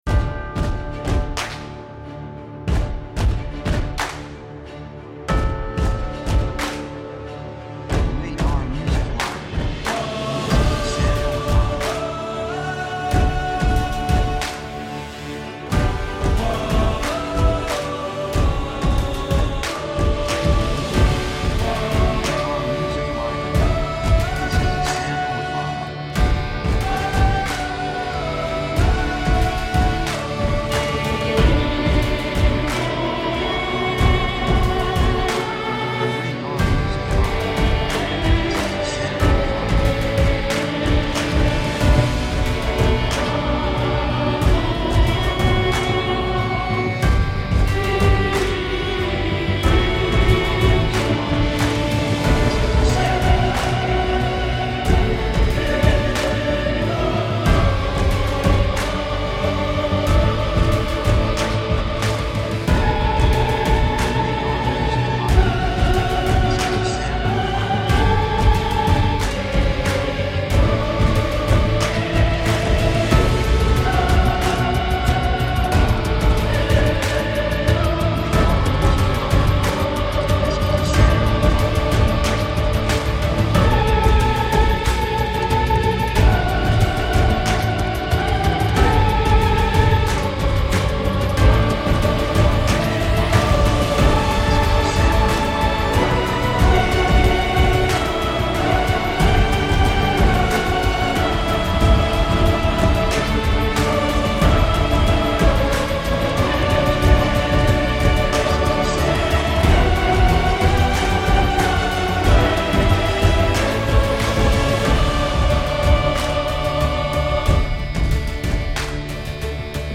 雰囲気高揚感, 決意, 喜び
曲調ポジティブ
楽器エレキギター, パーカッション, ストリングス, ボーカル, 手拍子
サブジャンルアクション, オーケストラハイブリッド
テンポミディアム